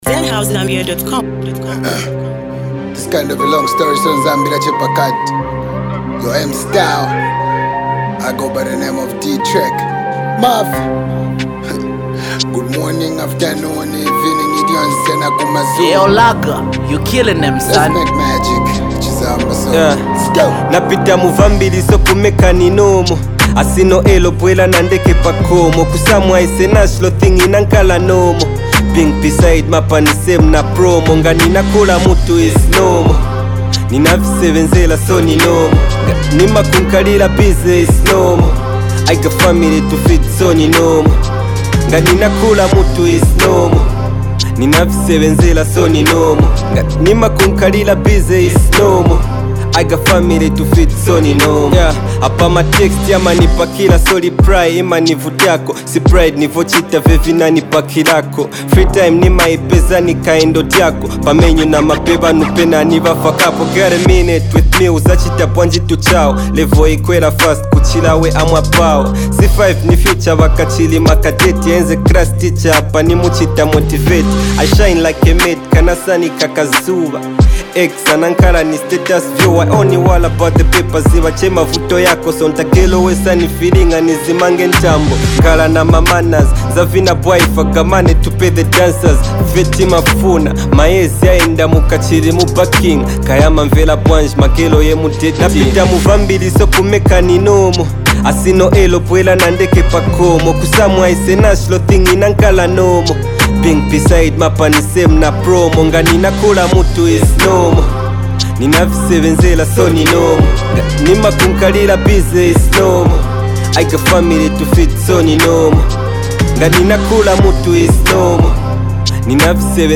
street anthem
With sharp lyrics and a hard-hitting beat